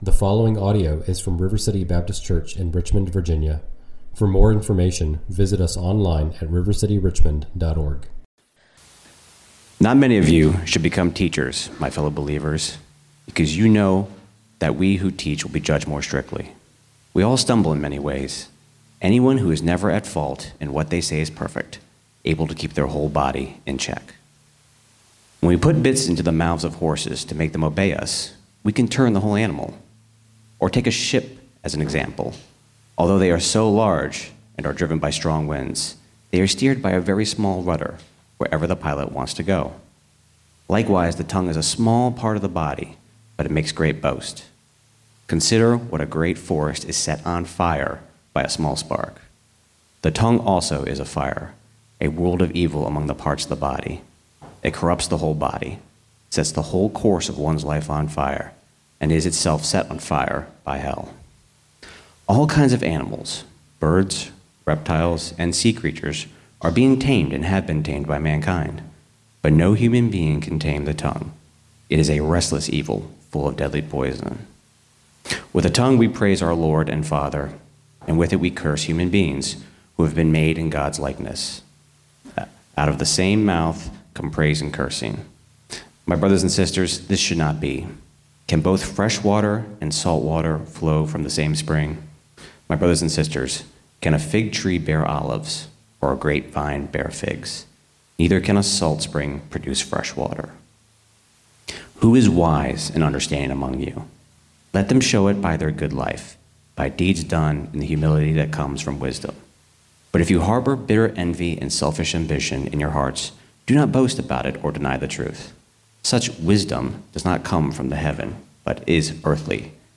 a new congregation in Richmond, Virginia.